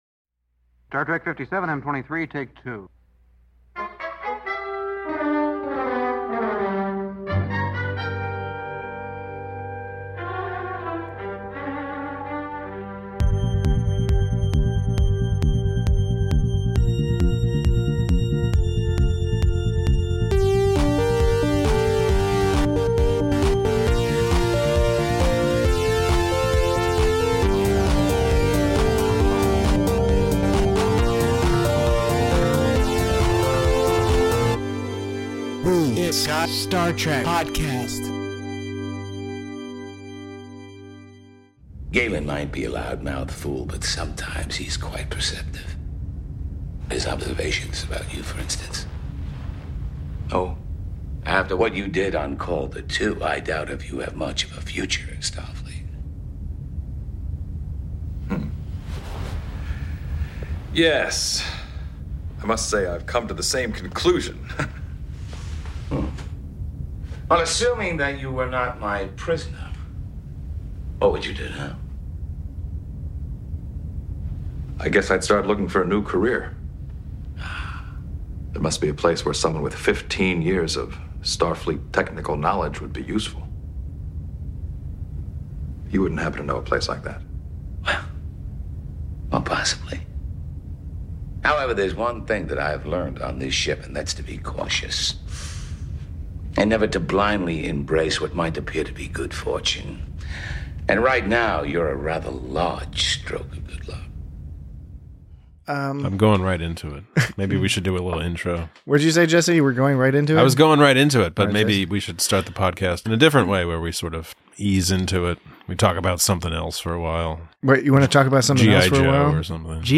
Commander Riker goes rogue after Captain Picard dies. Join your rag-tag gang of artifact-coveting hosts as they discuss 80's villains in the 90's, the combination of new ground and old hat common to most episodes of TNG's seventh season, and, of course, all manner of ploys, ruses, and maneuvers!